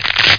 crush05.mp3